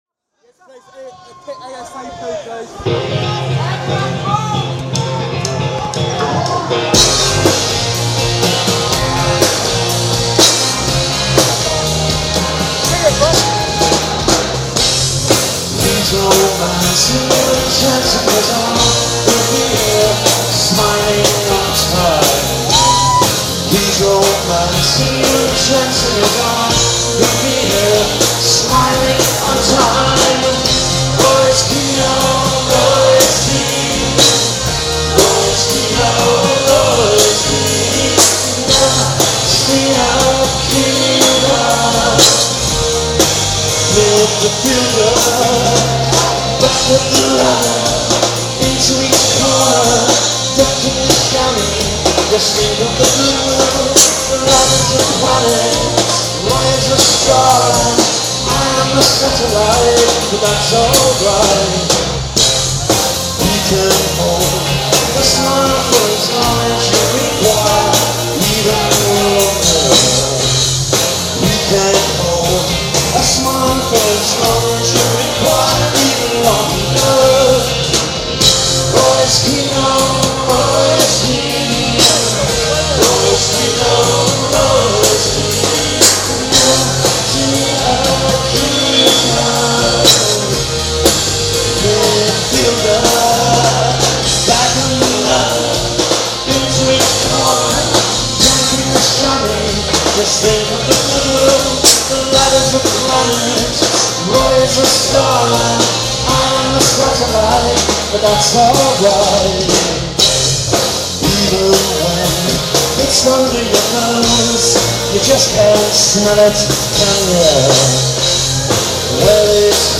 僕は、気づかれないようにマイクを装備して 録音した状態でダイブしてステージへ突撃していった。
1999年のイギリス。